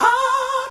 SG - Vox 12.wav